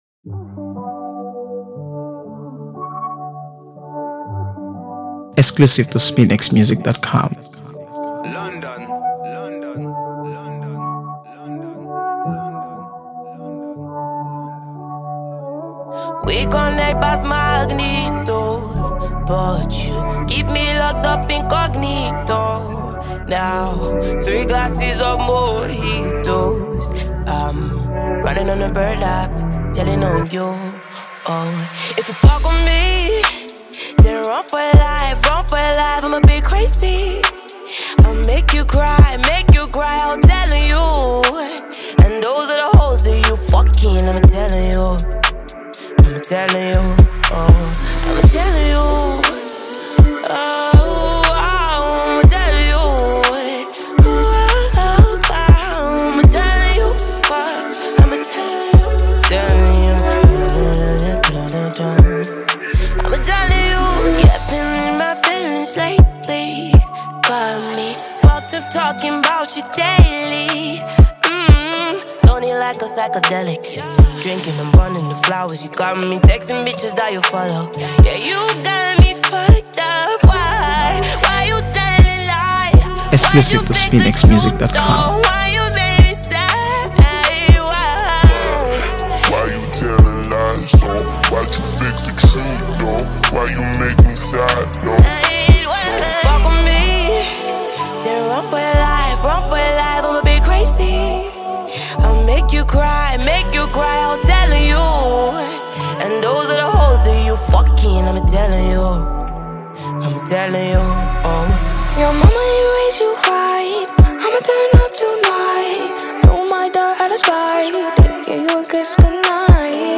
AfroBeats | AfroBeats songs
Nigerian singer-songwriter
who delivers a remarkable verse
dynamic vocal delivery